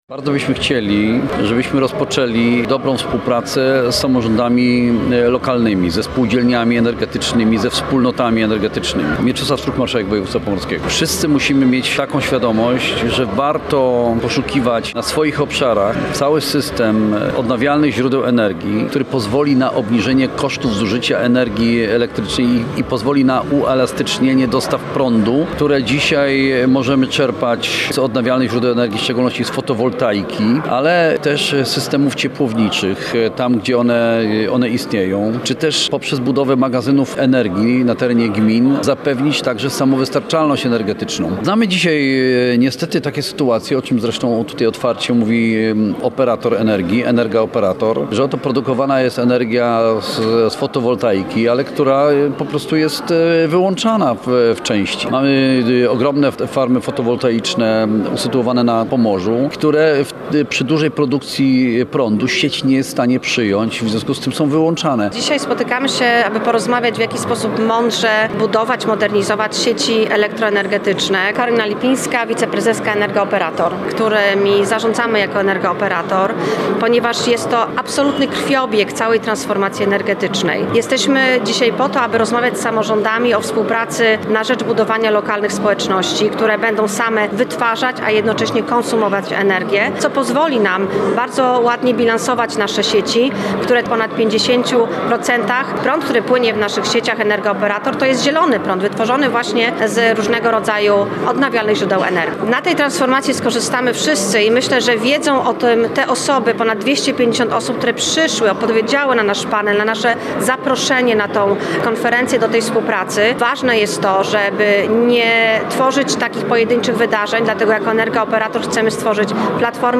Jakie korzyści mieszkańcom Pomorza niesie transformacja energetyczna? Jaka jest w niej rola samorządów? O tym mówili przedstawiciele Energa-Operator na konferencji dla samorządów.